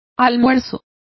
Complete with pronunciation of the translation of dinner.